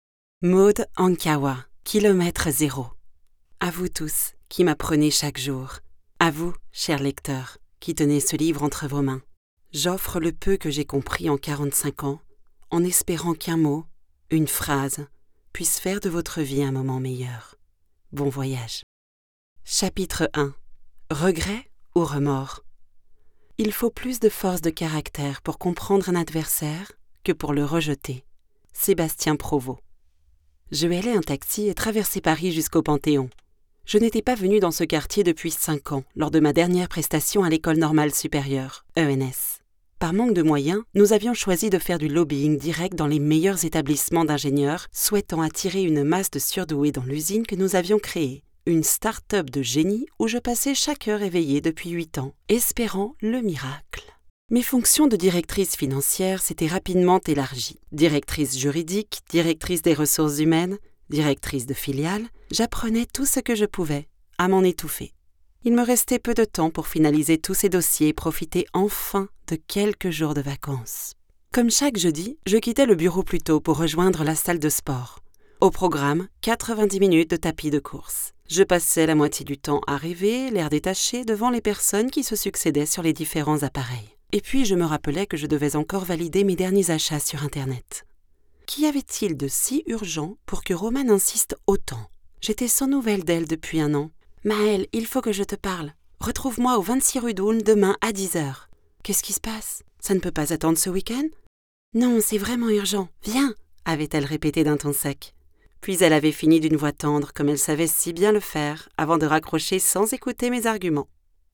Female
Approachable, Assured, Authoritative, Bright, Bubbly, Character, Children, Confident, Conversational, Corporate, Deep, Energetic, Engaging, Friendly, Gravitas, Natural, Reassuring, Smooth, Soft, Versatile, Warm, Witty
Microphone: TLM Neumann 103